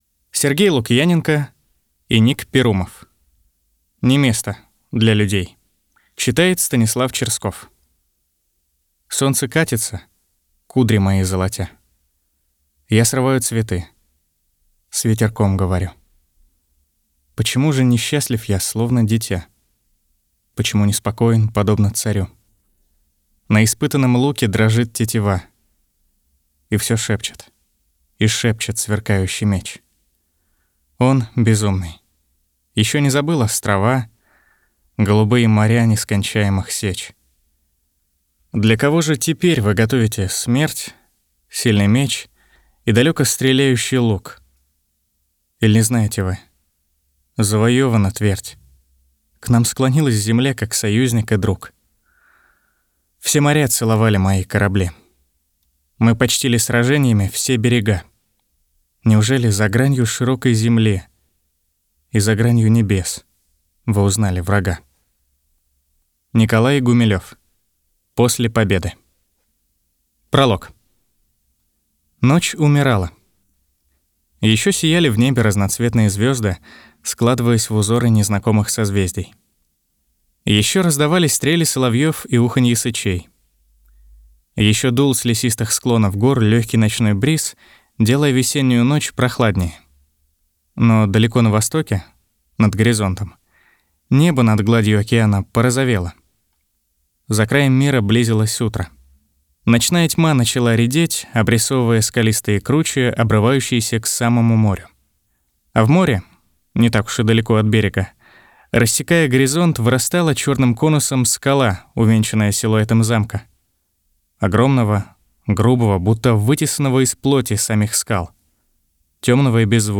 Аудиокнига
Муж, Аудиокнига
SE X1, Long VoiceMaster, Scarlett 2i2